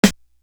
Soul Snare.wav